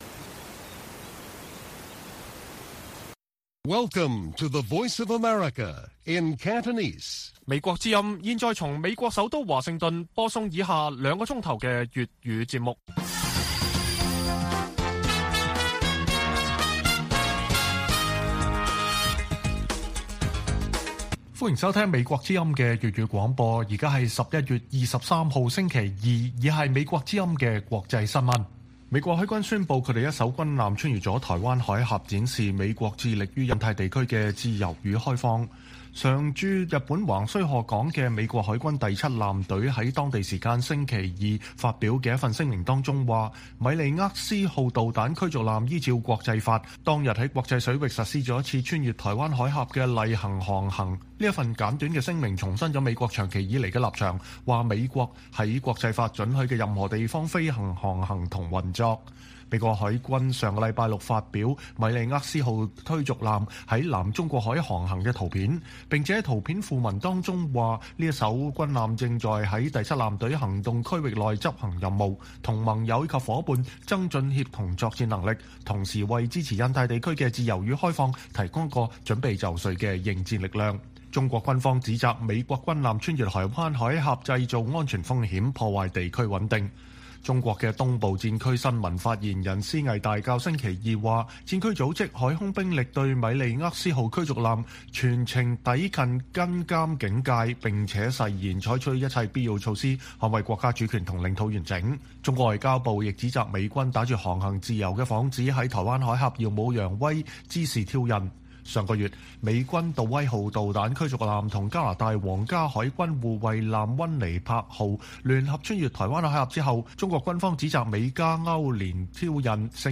粵語新聞 晚上9-10點: 美國軍艦在美中首腦會議後首次穿越台海